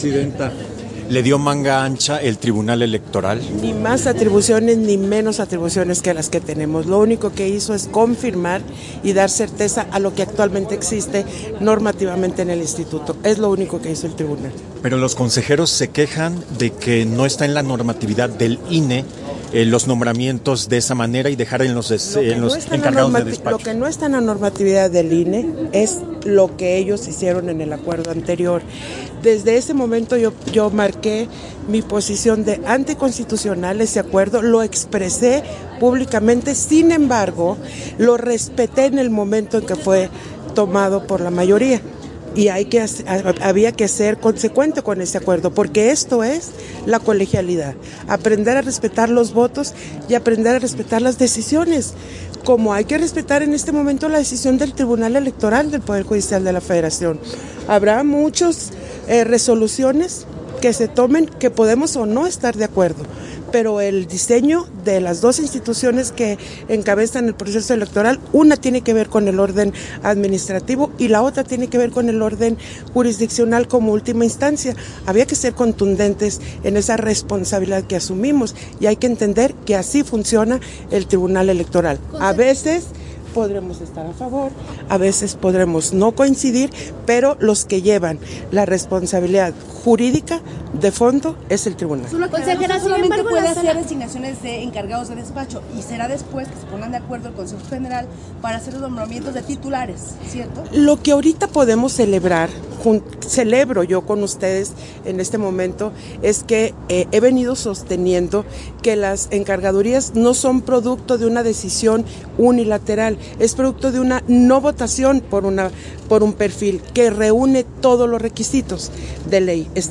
Entrevista a la Consejera Presidenta, Guadalupe Taddei, concedida a diversos medios de comunicación